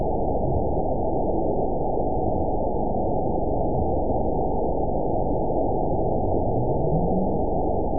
event 921973 date 12/23/24 time 23:35:04 GMT (4 months, 2 weeks ago) score 9.58 location TSS-AB02 detected by nrw target species NRW annotations +NRW Spectrogram: Frequency (kHz) vs. Time (s) audio not available .wav